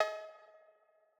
Cowbell (Blow).wav